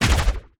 etfx_explosion_storm.wav